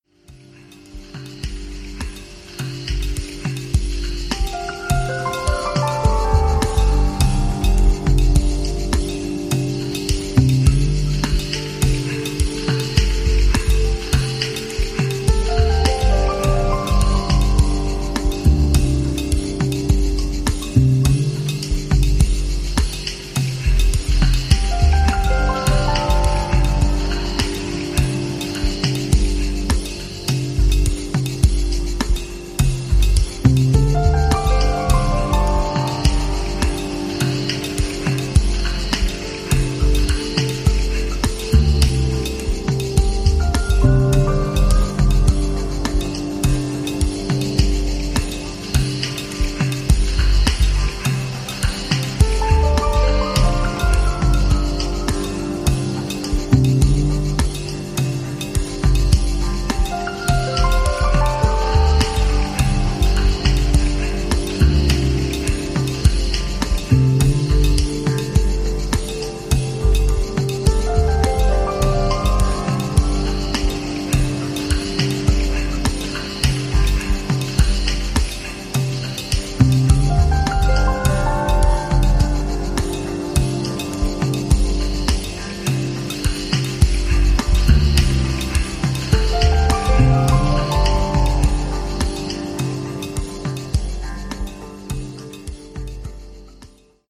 The album is built on the foundation of field recordings
Ranging from hypnotic downtempo grooves to deep house
Ambient
Deep house